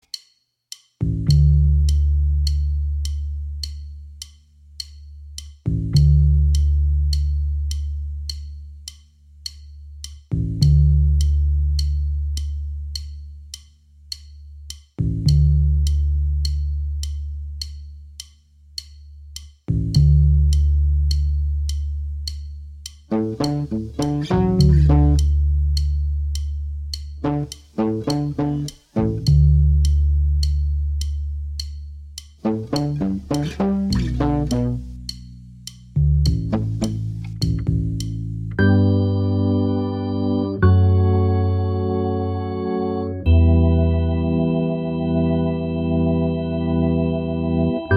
Minus Main Guitar R'n'B / Hip Hop 3:11 Buy £1.50